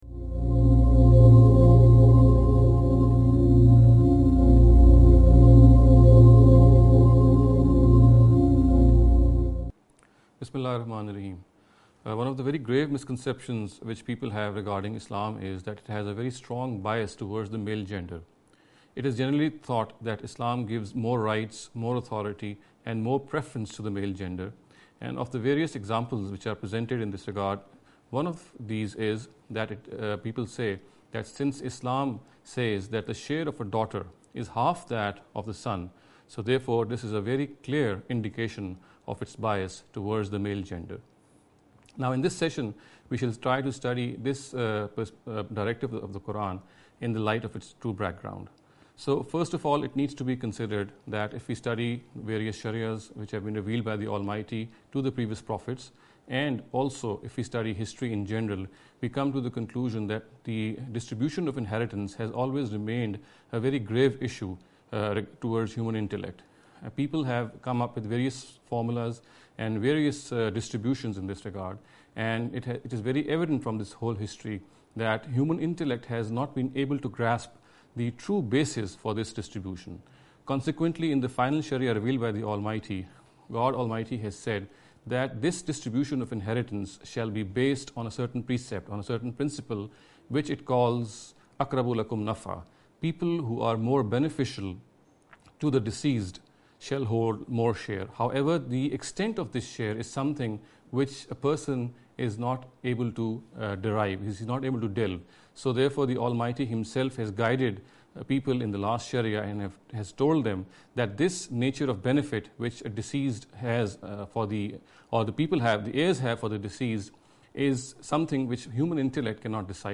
This lecture series will deal with some misconception regarding the Islam & Women.